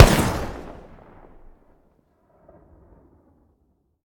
weap_mike203_fire_plr_atmos_01.ogg